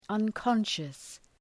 {ʌn’kɒnʃəs}